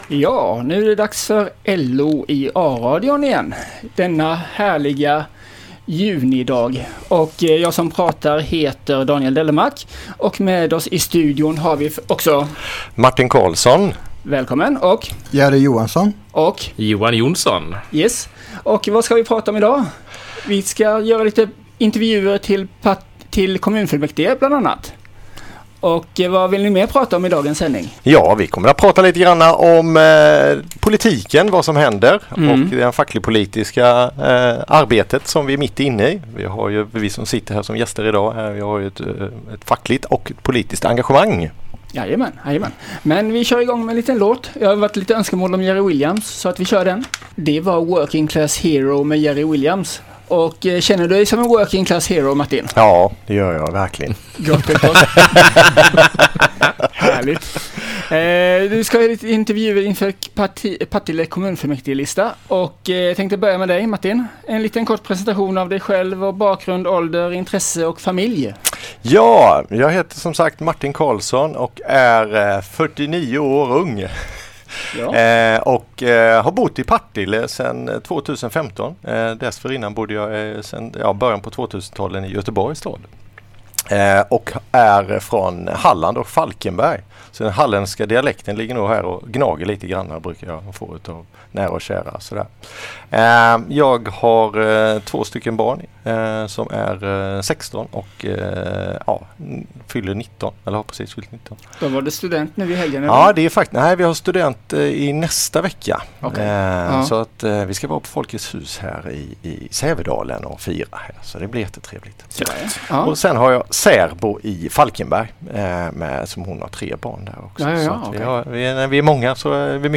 LO fackens A-radiosändning som pod